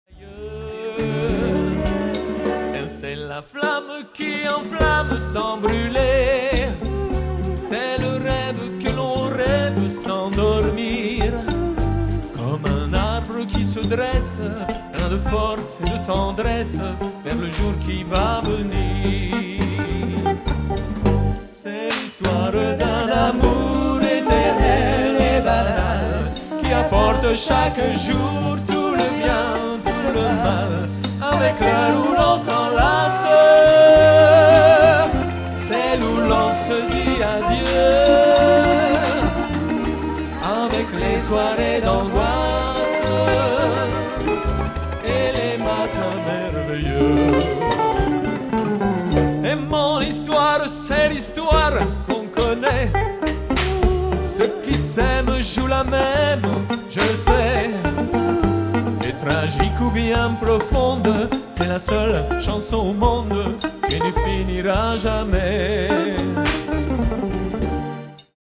boléro